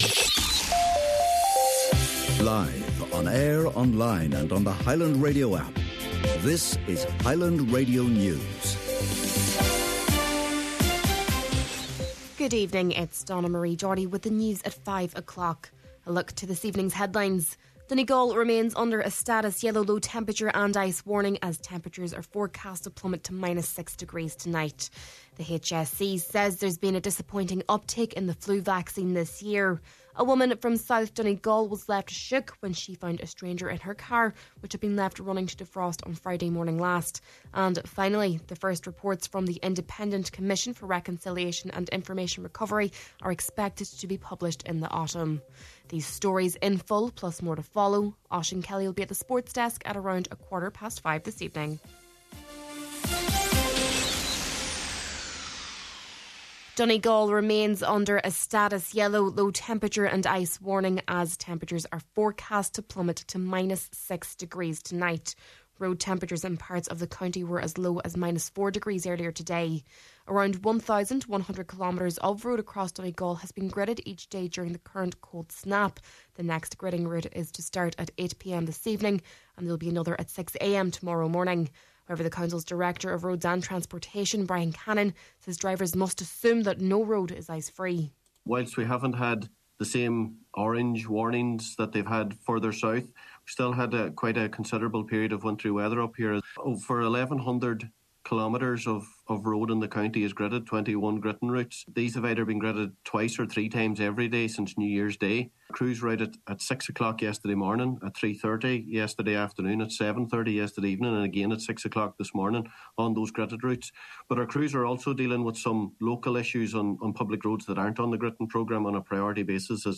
Main Evening News, Sport and Obituaries – Wednesday, January 8th